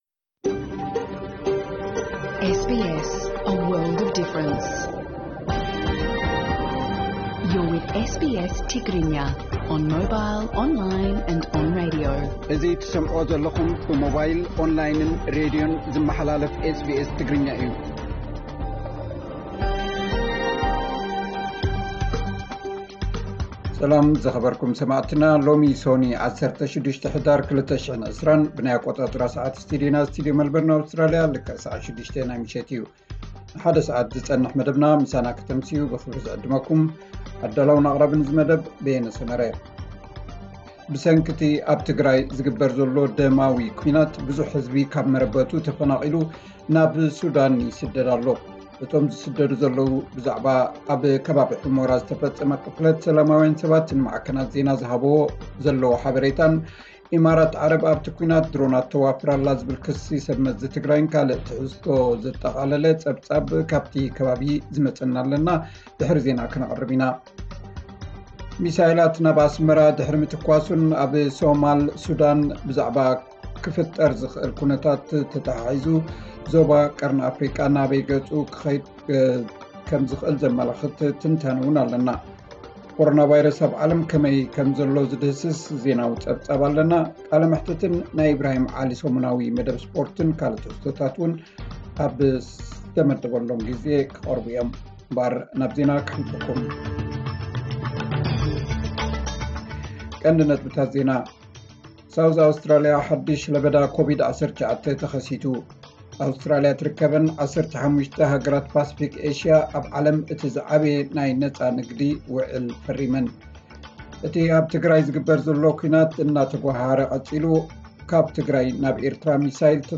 ኣውስትራሊያ ትርከበን 15 ሃገራት ፓሲፊክ ኤሽያ ኣብ ዓለም እቲ ዝዓበየ ናይ ነጻ ንግዲ ዉዕል ፈሪመን። (ዕለታዊ ዜና)